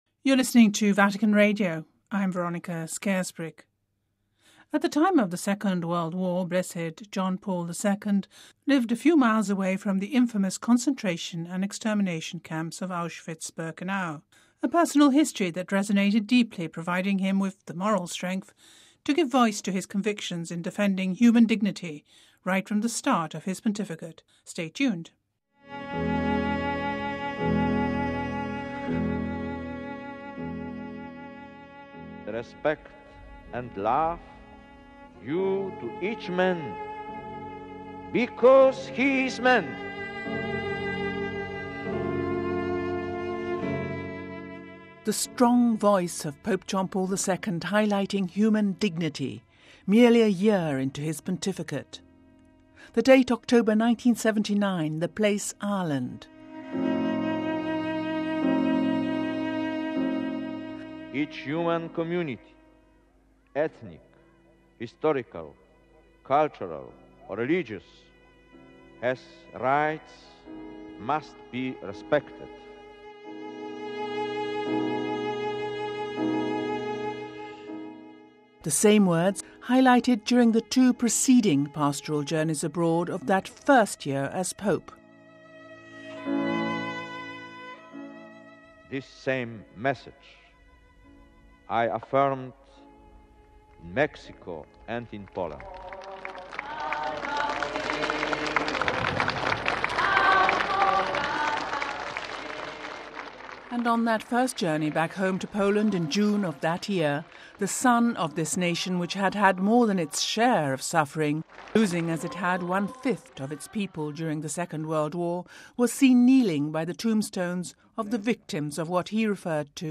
In this feature you can hear him express these convictions in Ireland and Mexico but above all during his first journey to his homeland as Roman Pontiff .